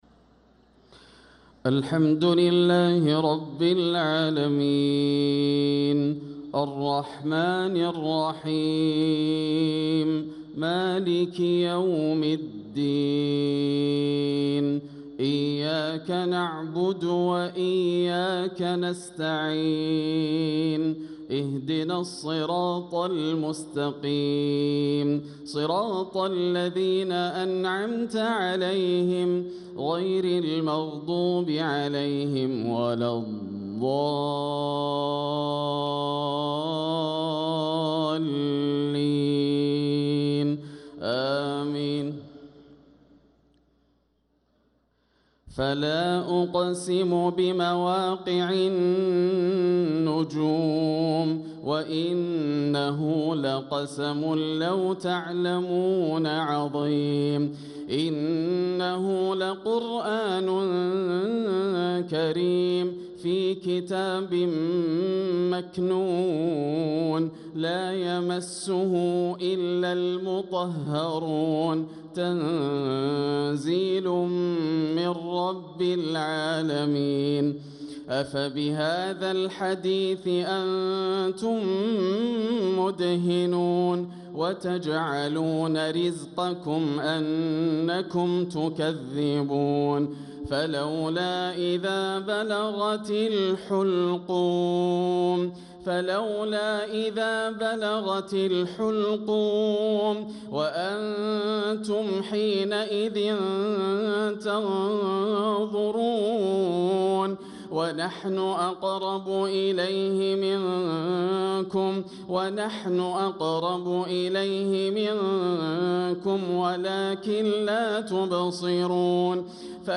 صلاة المغرب للقارئ ياسر الدوسري 16 رجب 1446 هـ